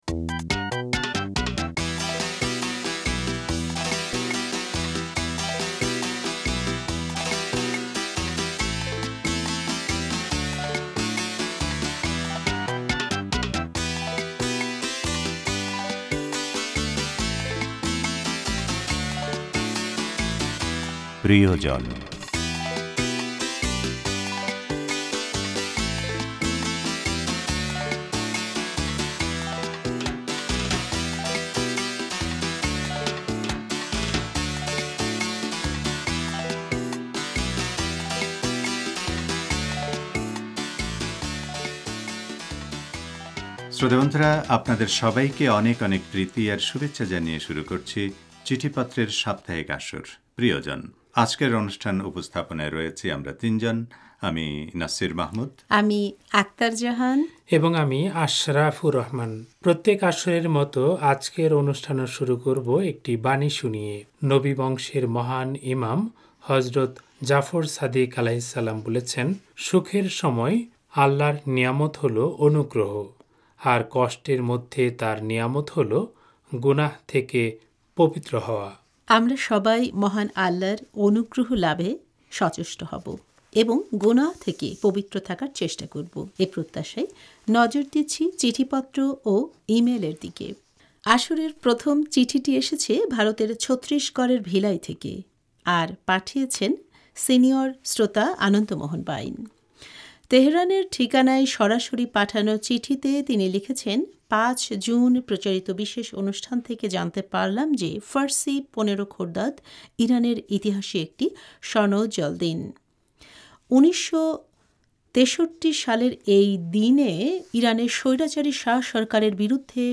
শ্রোতাবন্ধুরা, আপনাদের সবাইকে অনেক অনেক প্রীতি আর শুভেচ্ছা জানিয়ে শুরু করছি চিঠিপত্রের সাপ্তাহিক আসর 'প্রিয়জন'। আজকের অনুষ্ঠান উপস্থাপনায় রয়েছি আমরা তিনজন।...